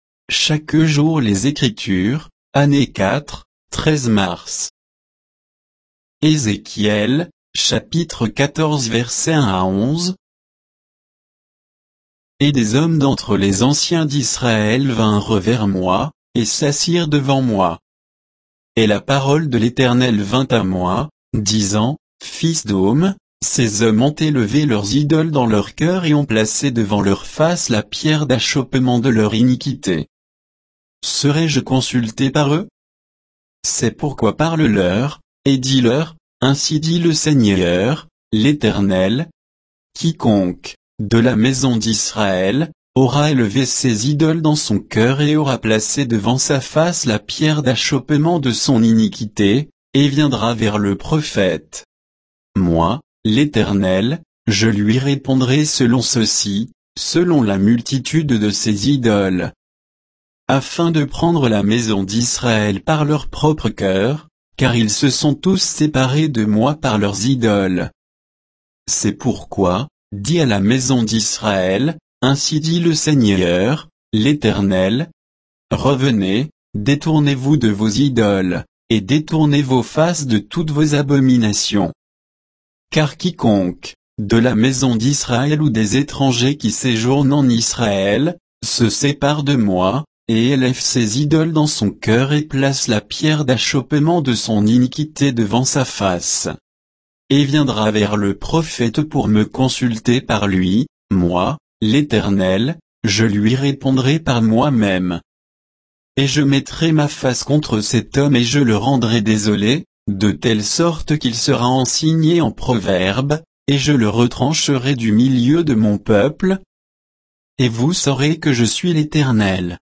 Méditation quoditienne de Chaque jour les Écritures sur Ézéchiel 14